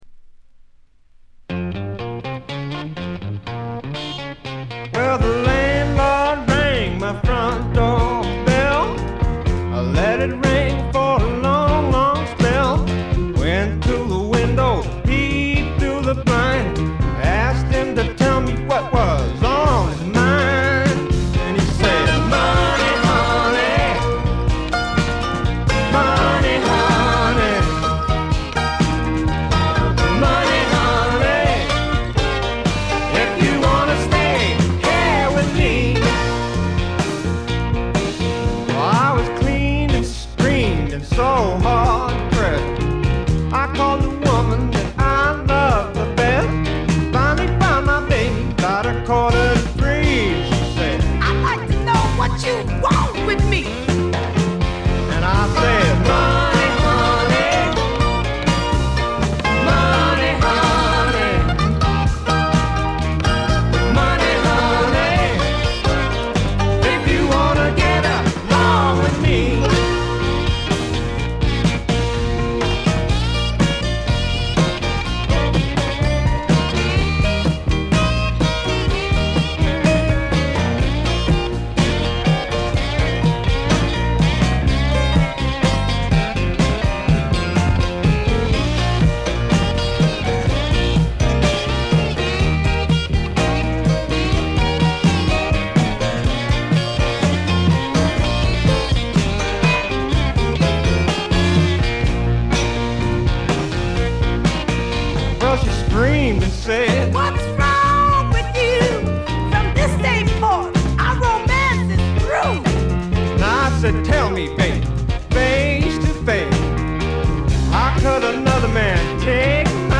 軽微なチリプチがわずかに出る程度。
試聴曲は現品からの取り込み音源です。
guitar, bass, mandolin, vocals